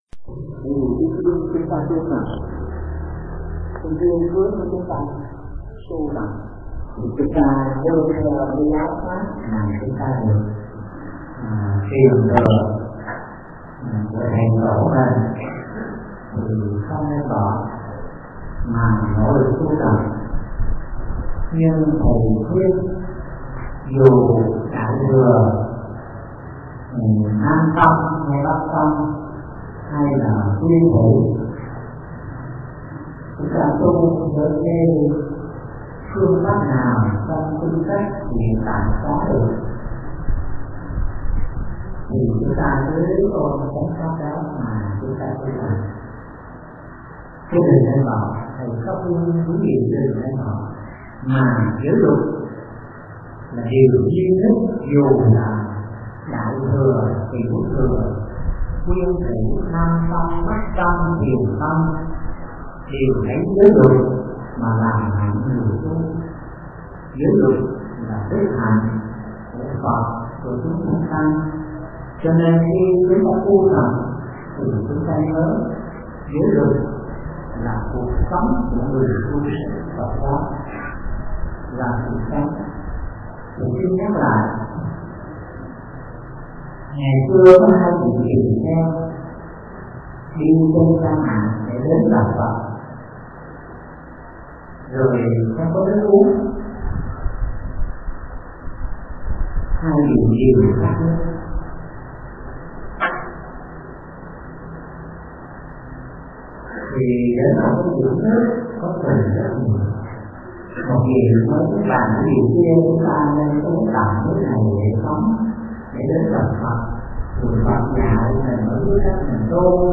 THẦY DẠY TẠI TRƯỜNG HẠ 02 - GIỚI LUẬT LÀ ĐỜI SỐNG CỦA NGƯỜI TU
Người nghe: Tu sinh